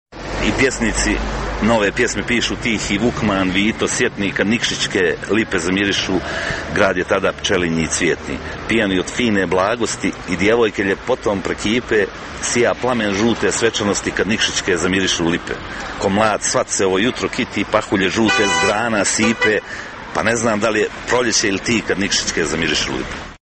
govori stihove...